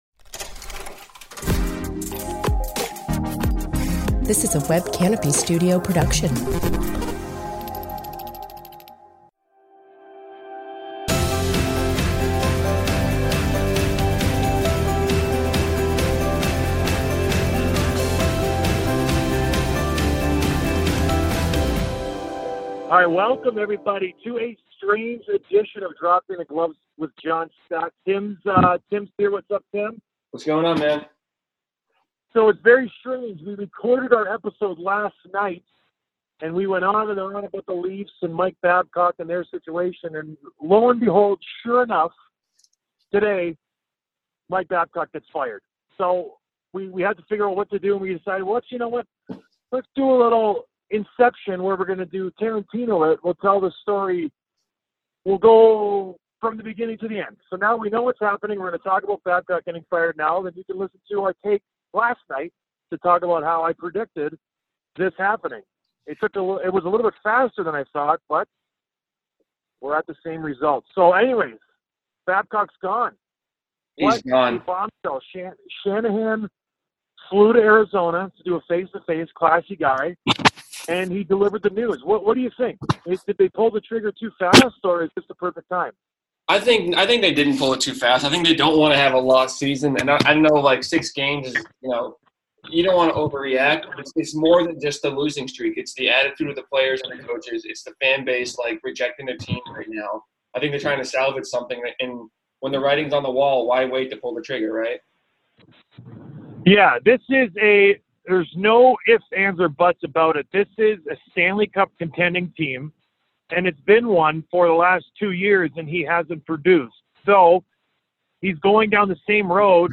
Sorry for the audio quality!